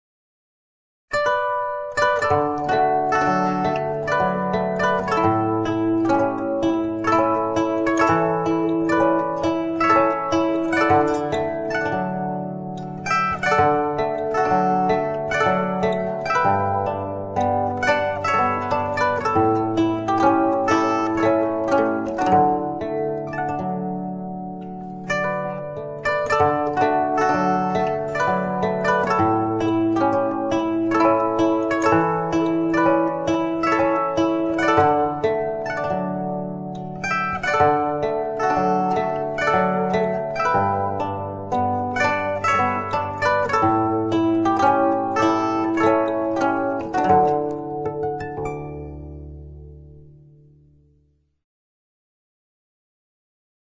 zither.mp3